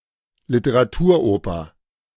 Literaturoper (German: [lɪtəʁaˈtuːɐ̯ˌʔoːpɐ]
De-Literaturoper.ogg.mp3